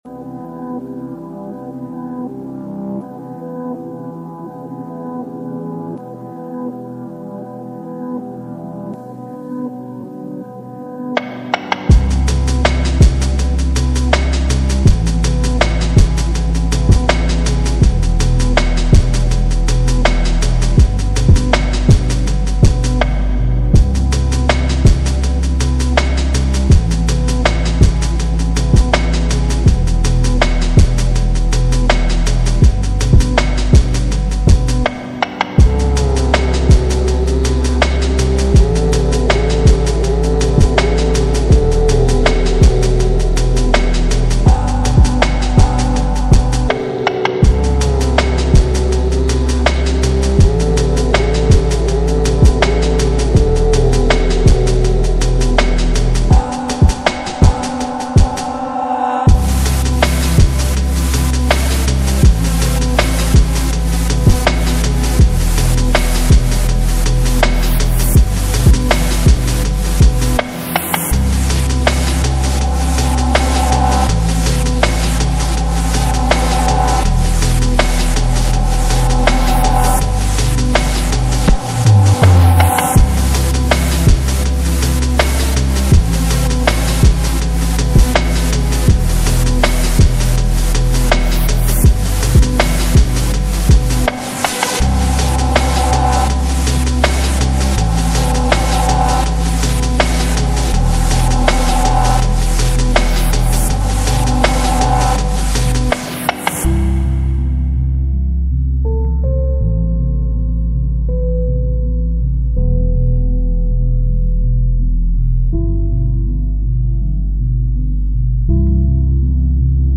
slowed - reverb